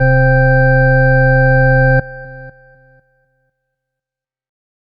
Organ (3).wav